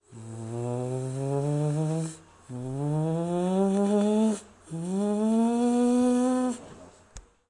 标签： g bluegrass oldtime bump
声道立体声